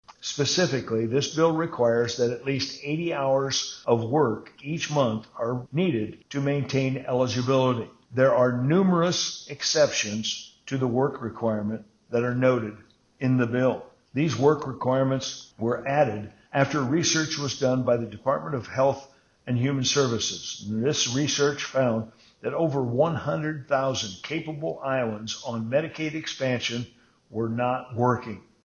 Iowa House District 18 Representative Tom Moore explains…